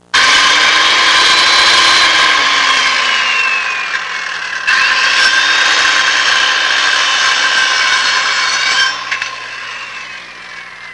Electric Saw Sound Effect
Download a high-quality electric saw sound effect.
electric-saw-1.mp3